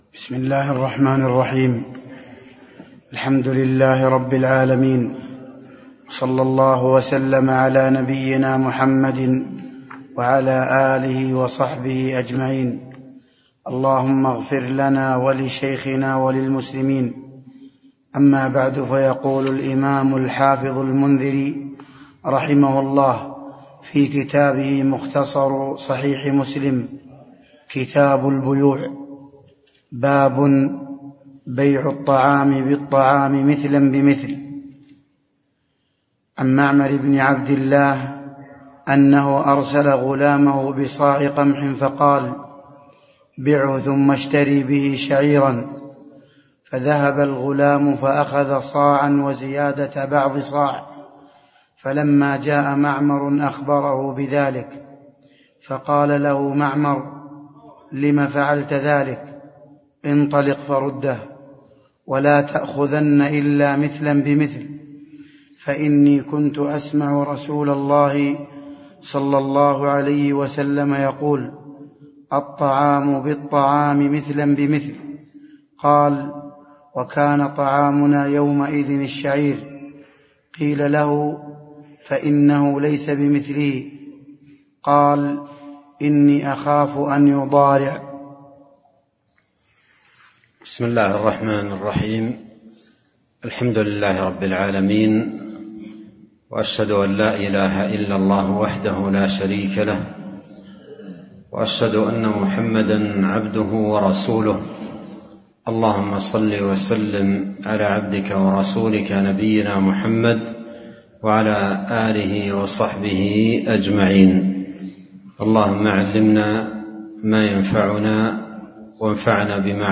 تاريخ النشر ٣٠ محرم ١٤٤٣ هـ المكان: المسجد النبوي الشيخ